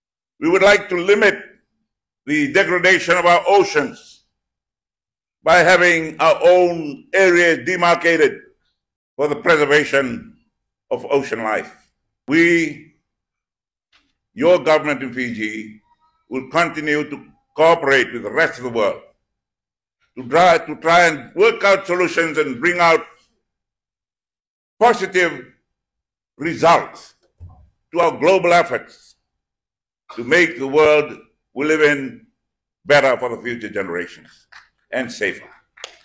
Prime Minister Sitiveni Rabuka delivered a compelling address to the Fijian diaspora in Australia, shedding light on the pressing issue of climate change and its adverse impacts on island nations, particularly Fiji.